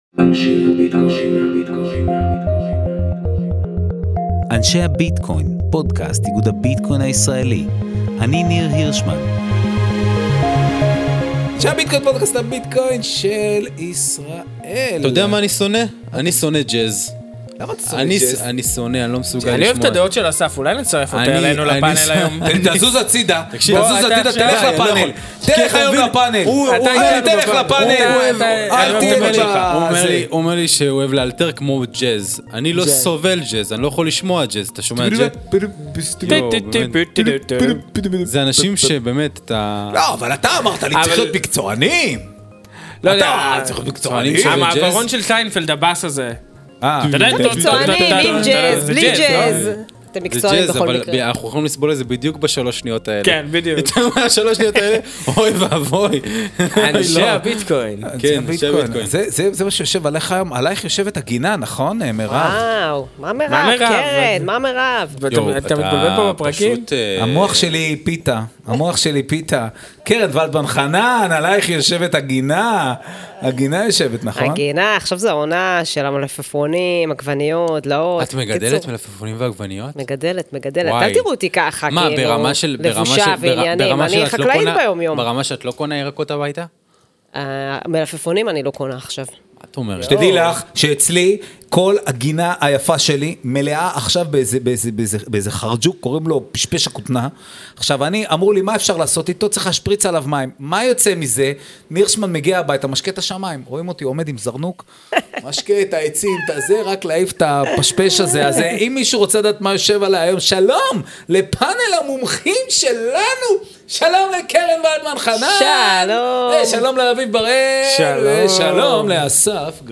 פאנל המומחים